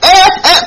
sound-undo.mp3